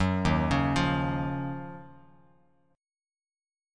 D#m9 chord